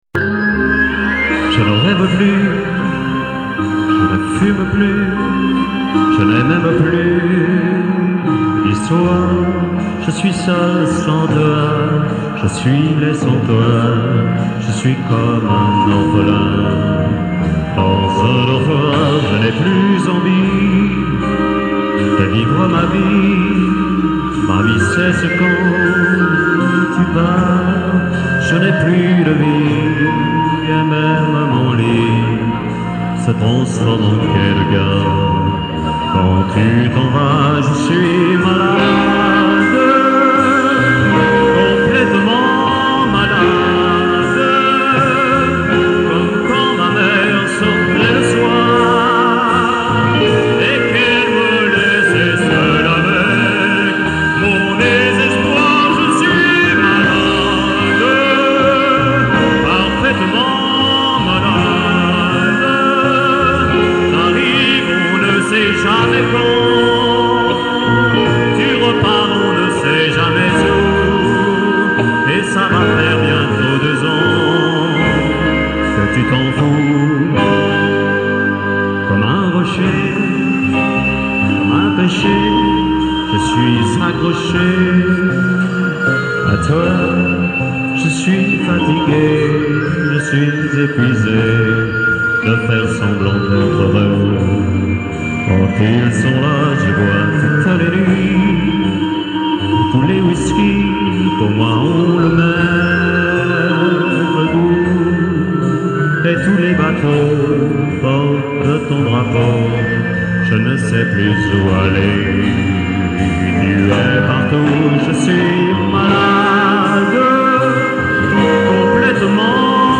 Orchestre
Chant